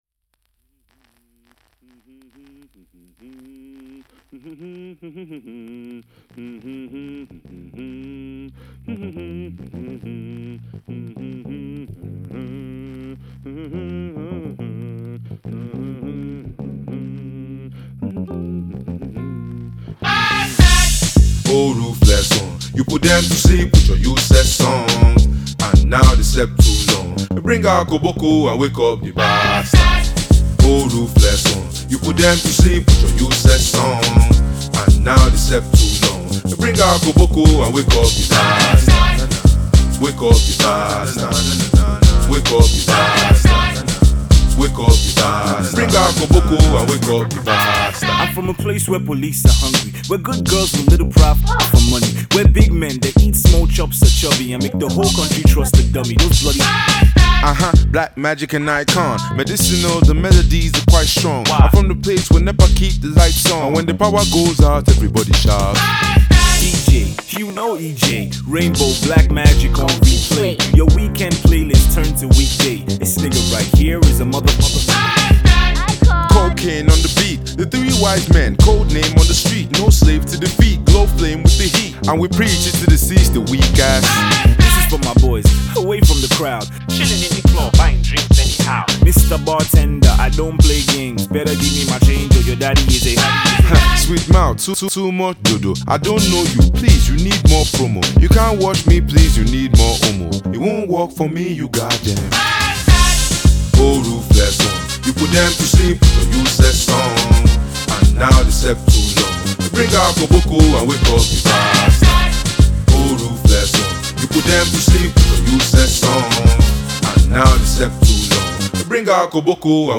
blends Jazz, Hip-Hop and Afrobeat with a unique formula